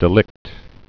(dĭ-lĭkt)